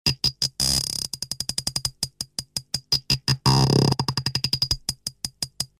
bucephalus bouncing ball ball effect
bucephalus-bouncing-ball-ball-effect.mp3